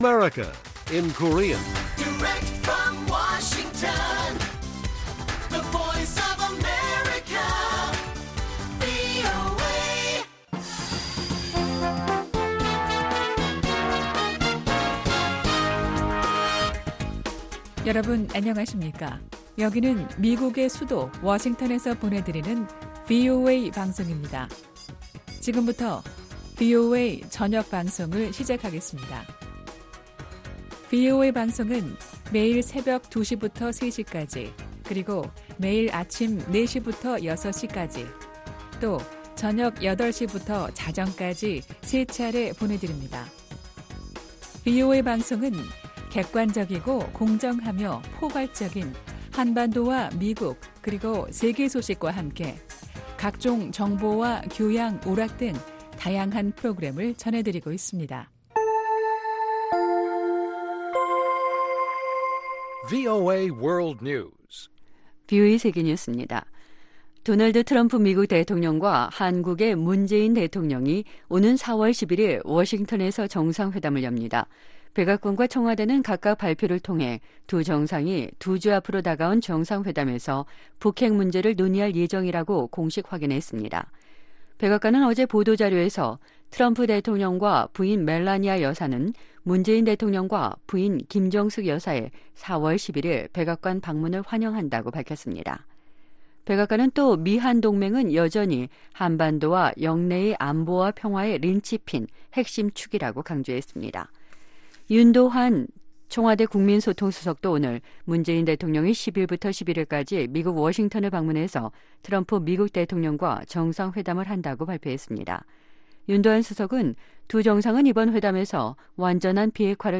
VOA 한국어 간판 뉴스 프로그램 '뉴스 투데이', 2019년 3월 29일 1부 방송입니다. 미 국무부는 우리는 북한과의 외교가 앞으로 나아갈 수 있을 것으로 여전히 낙관한다고 밝혔습니다. 미-한 정상이 다음달 11일 워싱턴에서 정상회담을 열고 북한 문제 등을 논의한다고 백악관이 밝혔습니다.